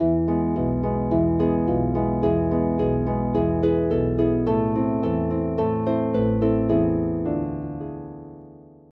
arranged for solo lever and pedal harp